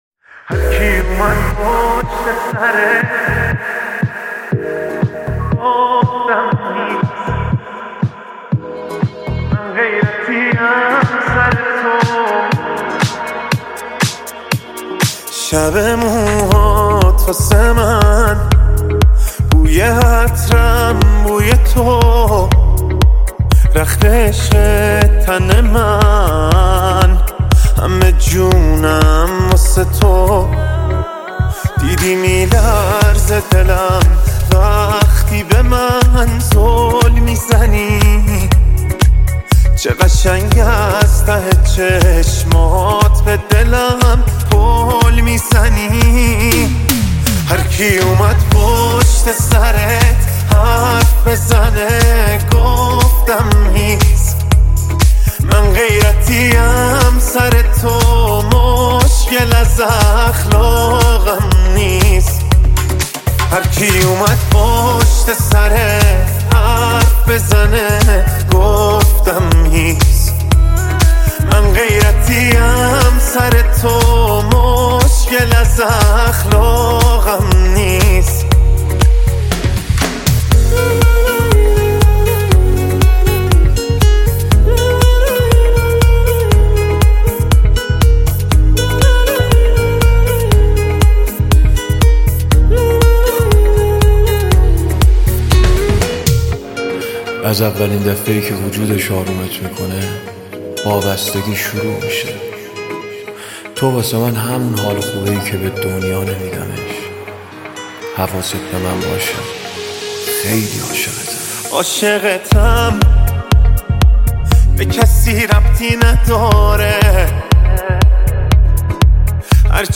پاپ ریمیکس